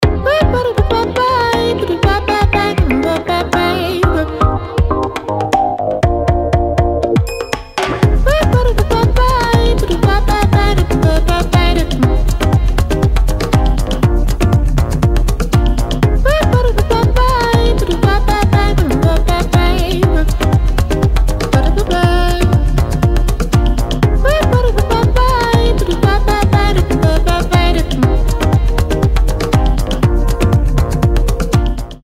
• Качество: 320, Stereo
поп
dance
красивый женский голос
Красиво напевает игривый мотивчик под приятную музыку